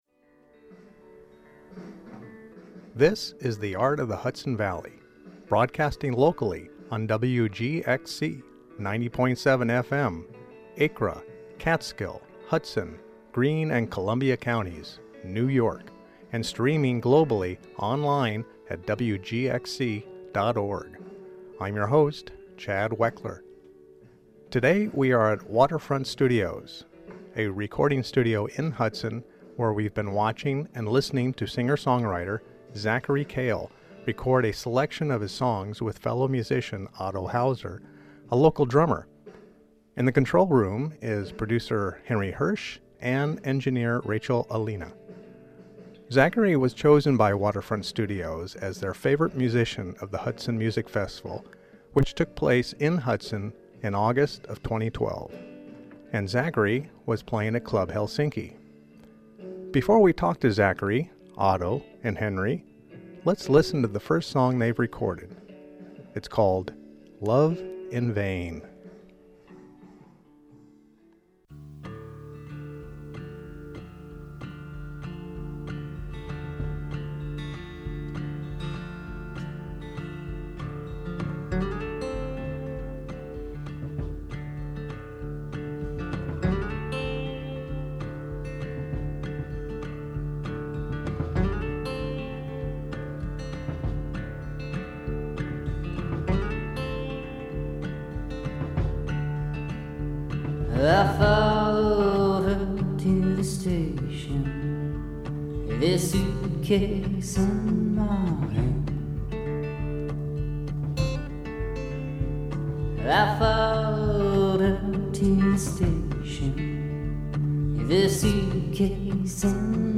We are on location at Waterfront Studios